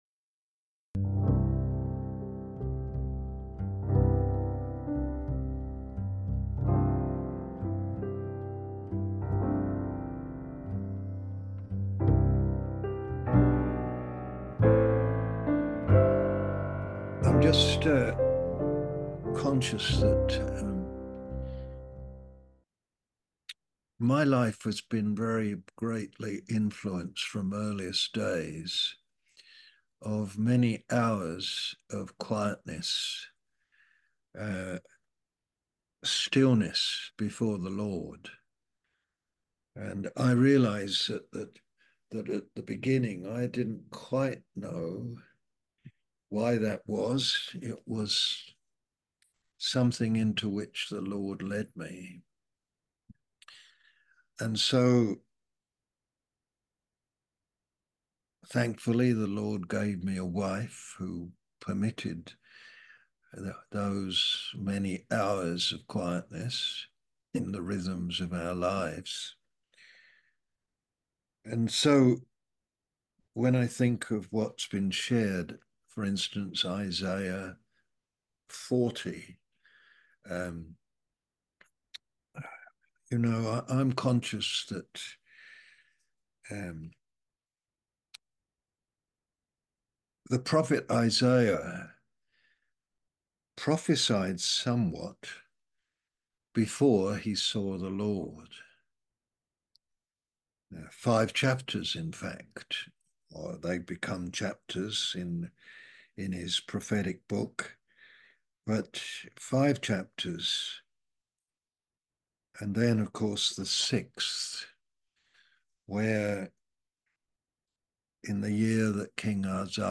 A message from the series "US Mens Meetings."